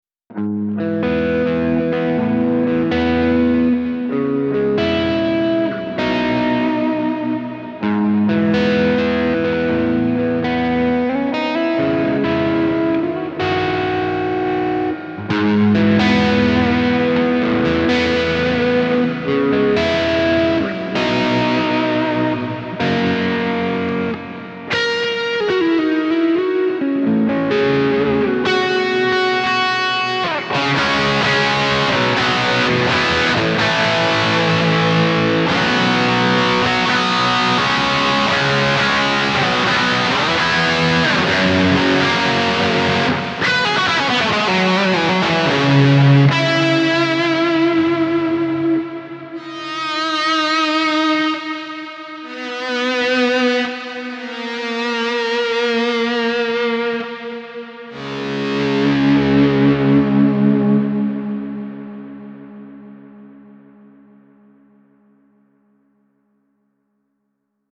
This Amp Clone rig pack is made from a SLO II Synergy Module with a matching cab.
NRM_AMB - Scene to switch between normal and ambient modes
RAW AUDIO CLIPS ONLY, NO POST-PROCESSING EFFECTS